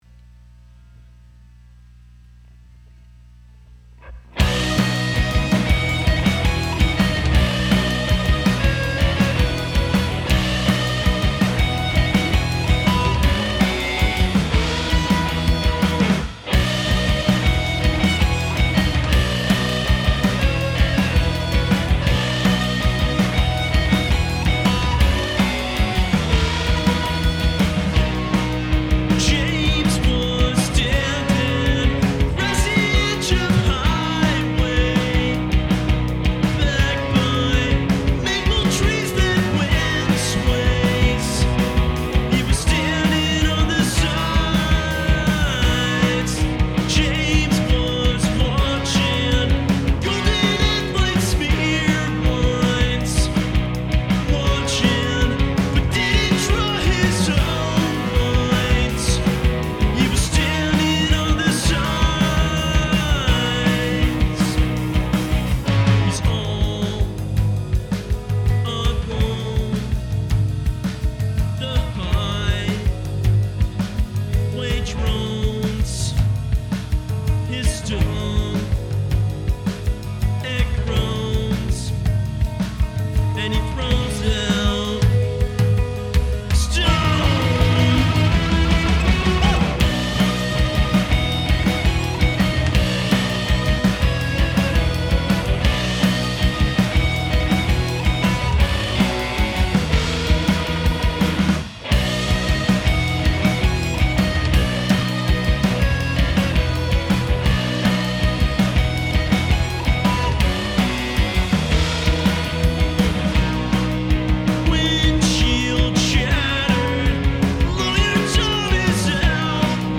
Here is a pretty straightforward rock track. I would love some pointers on levels, EQ, etc. Thanks